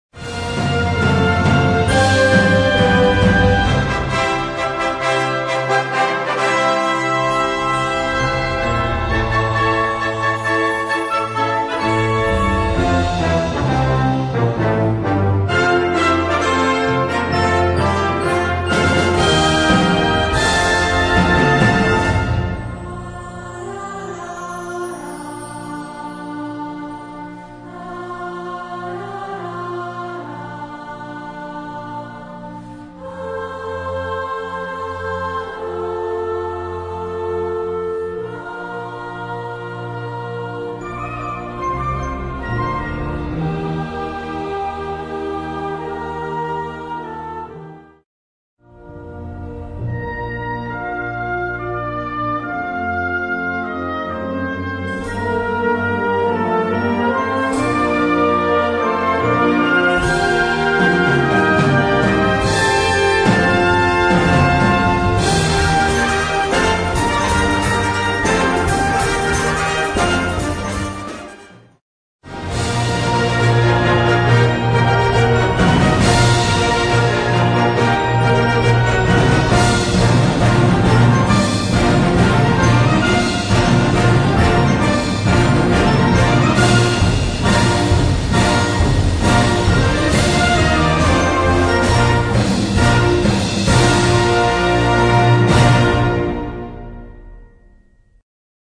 Noten für Brass Band.